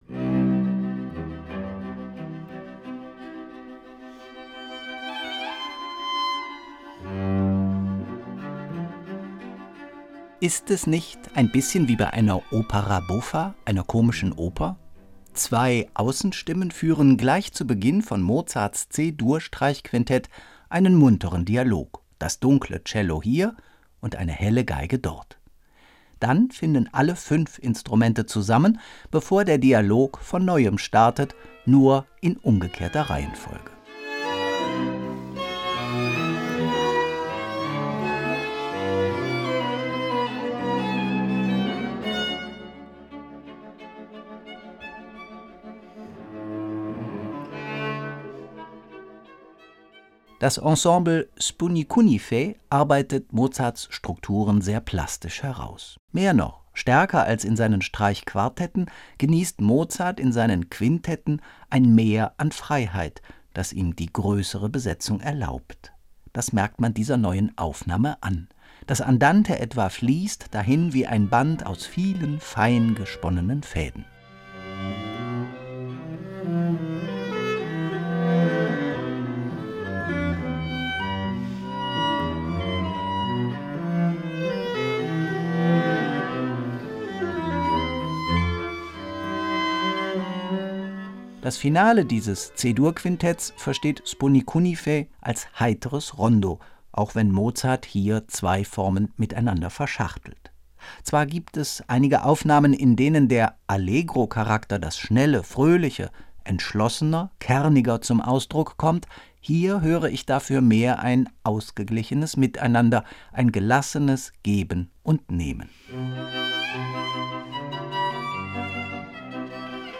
So bleiben einige Details etwas unscharf.